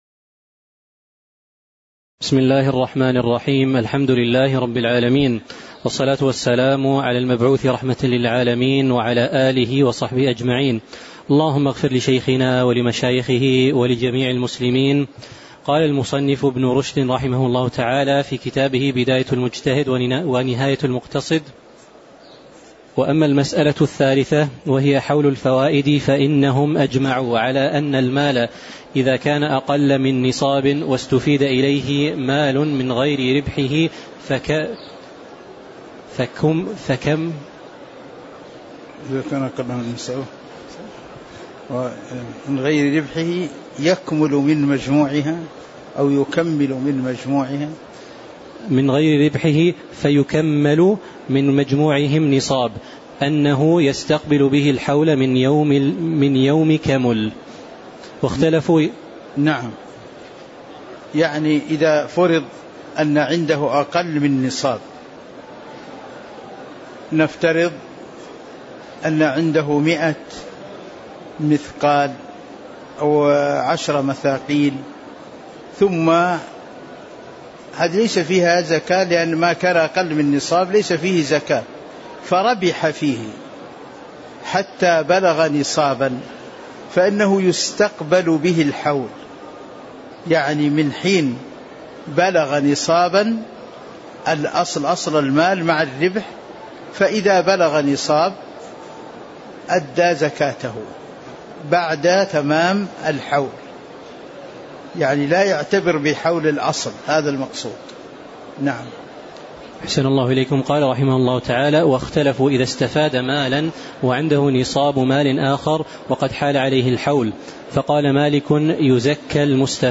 تاريخ النشر ٤ ذو القعدة ١٤٤٥ هـ المكان: المسجد النبوي الشيخ